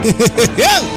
laugh1
Category: Comedians   Right: Personal